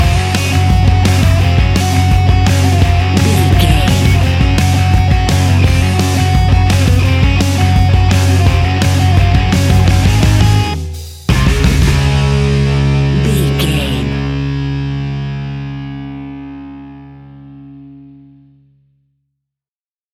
A great piece of royalty free music
Uplifting
Ionian/Major
D♭
hard rock
distortion
punk metal
instrumentals
Rock Bass
heavy drums
distorted guitars
hammond organ